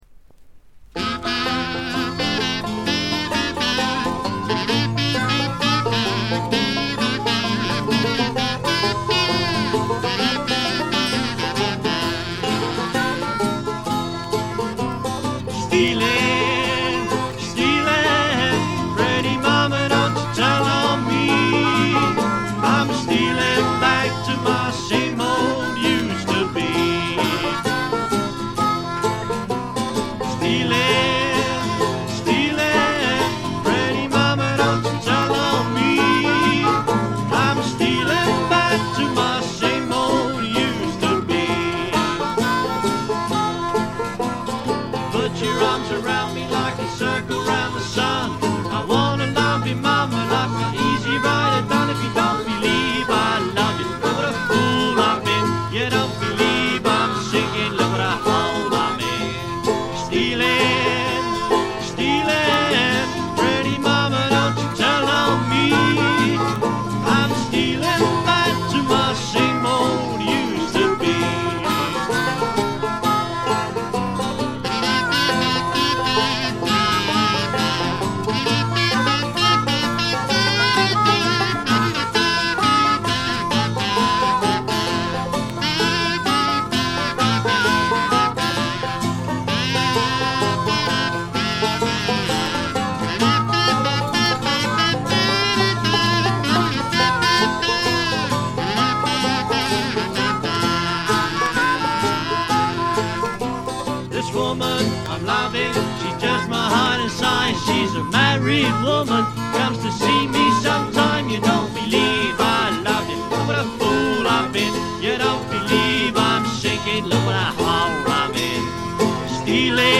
軽微なバックグラウンドノイズのみ。
知る人ぞ知る英国に残るジャグバンドの至宝す。
試聴曲は現品からの取り込み音源です。
lead vocals, kazoo, guitar, banjo, bass drum
washboard, harmonica, jug, hoot